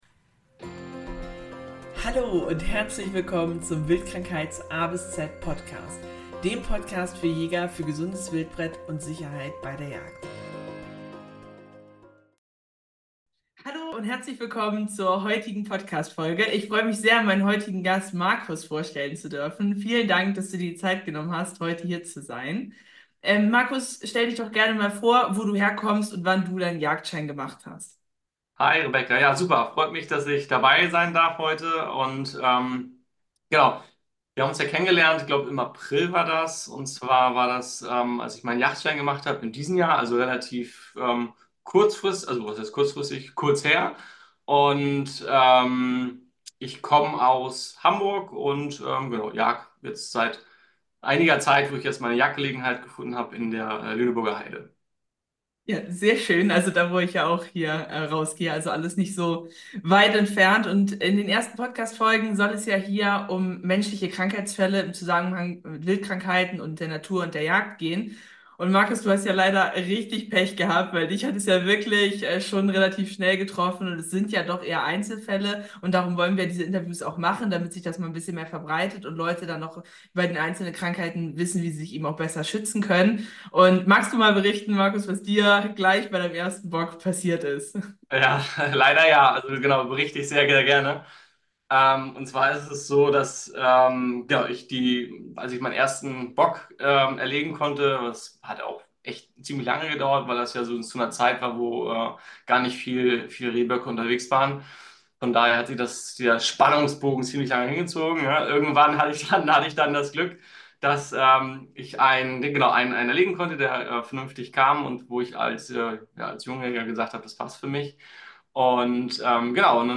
Beschreibung vor 1 Jahr Folge 3: Erster Rehbock mit Folgen - ein Sarkosporidien-Erfahrungsbericht In dieser Folge spreche ich mit einem Jäger, der sich nach dem Verzehr seines ersten Rehrückens gefragt hat, ob er das restliche Wildbret noch verwerten kann. Er berichtet, was ihm nach dem Wildbretgenuss geschah, welche Herausforderungen er mit der Abklärung der Genusstauglichkeitsfrage zu meistern hatte und wie das Rätsel gelöst werden konnte.